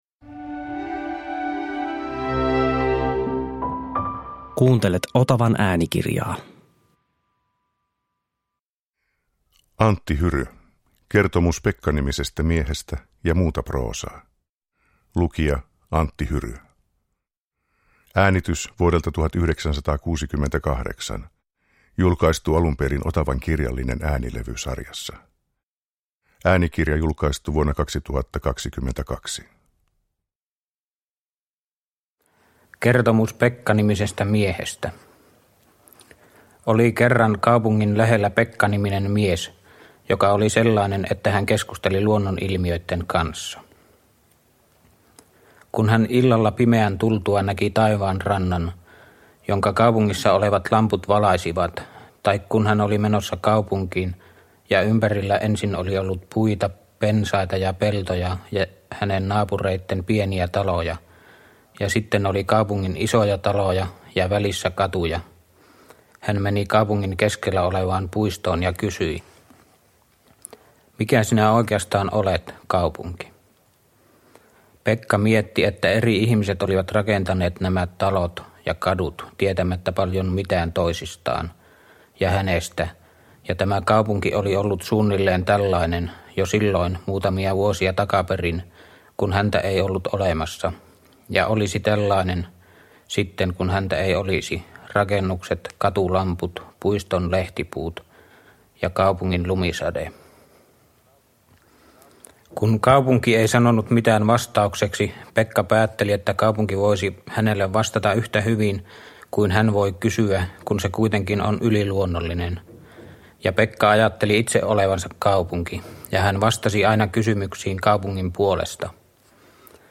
Hiljaisen kerronnan mestari äänessä!
Äänitteellä vuodelta 1968 hän lukee otteita teoksistaan - ja yhtä ainutkertaista kuin on Hyryn proosa, on hänen tapansa lukea tätä proosaa: suoraa, rehellistä ja koristelematonta.
Uppläsare: Antti Hyry, A. W. Yrjänä